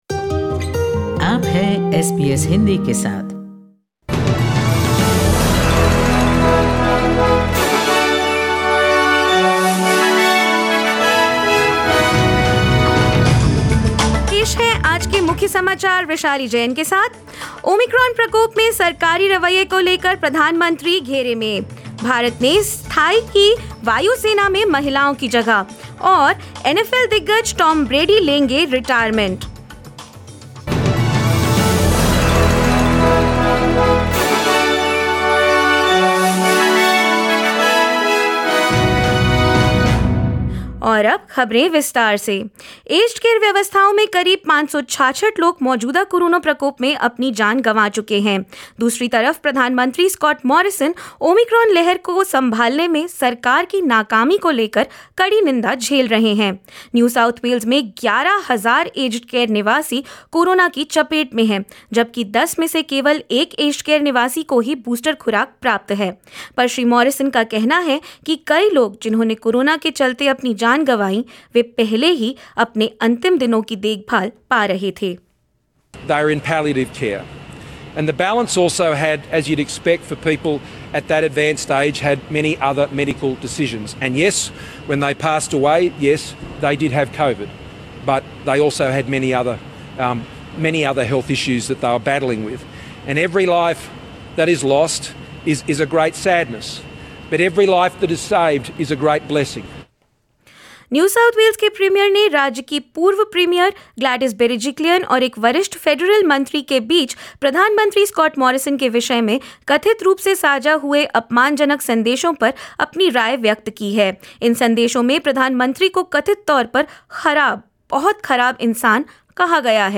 In this latest SBS Hindi bulletin: Prime Minister Scott Morrison has defended the government's response to omicron outbreak in the country ; Indian Air Force makes permanent place for women pilots; NFL star Tom Brady announces his retirement and more.